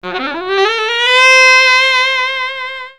JAZZ SCALE.wav